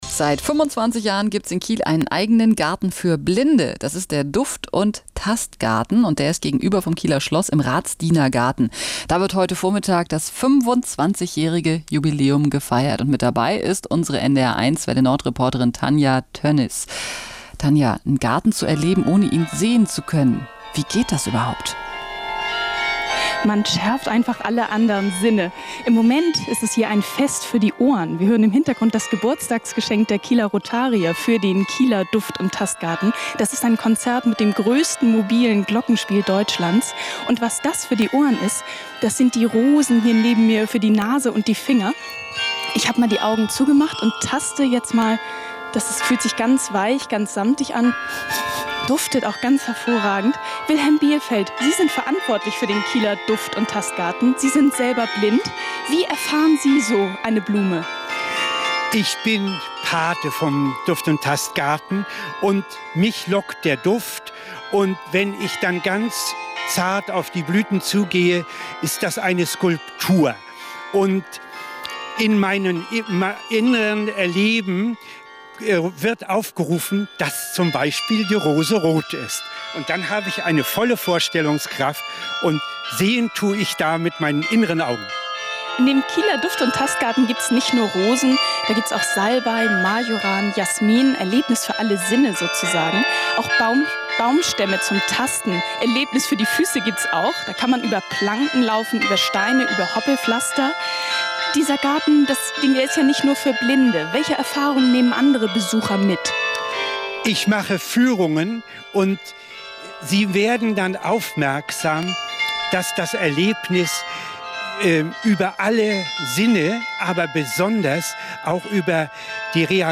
Und so berichtete die © NDR1 Welle Nord direkt vom Ort des Geschehens.